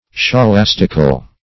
Scholastical \Scho*las"tic*al\